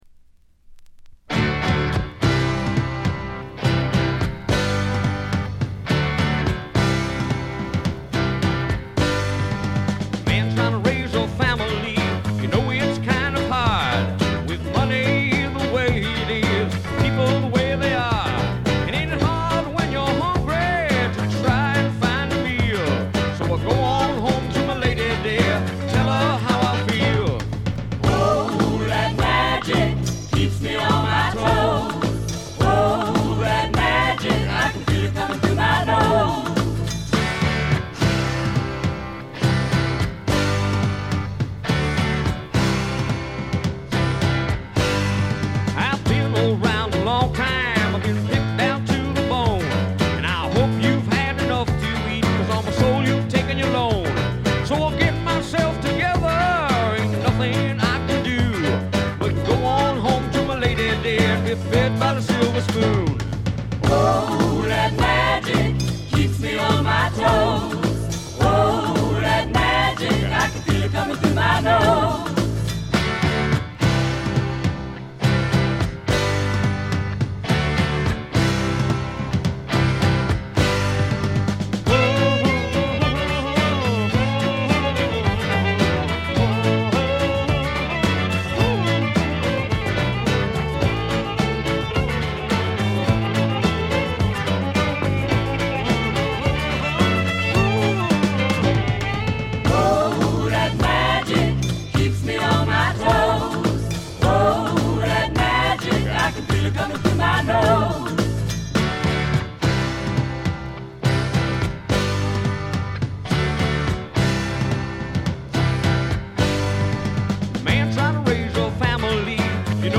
軽微なバックグラウンドノイズ、チリプチ程度。
知られざるスワンプ系シンガー・ソングライターの裏名盤です。
試聴曲は現品からの取り込み音源です。